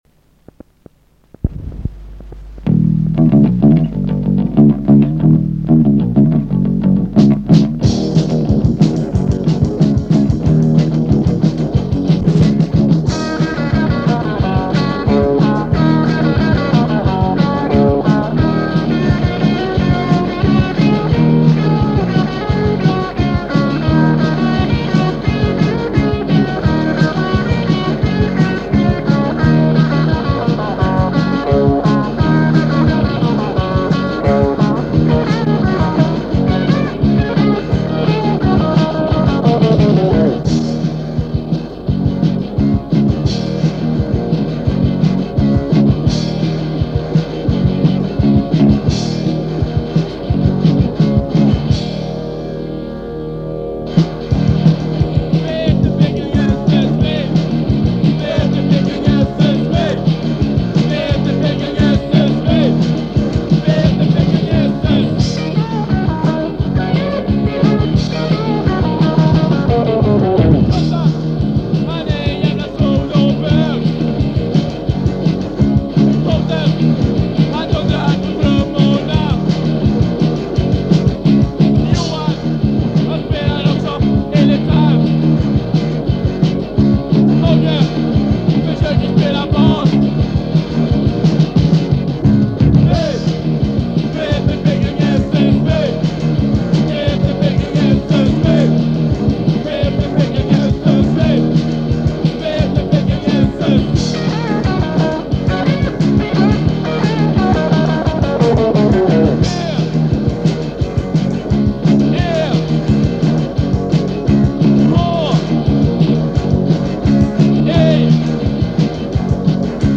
Från bandets första demo.